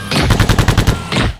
bellato_guardtower_attack.wav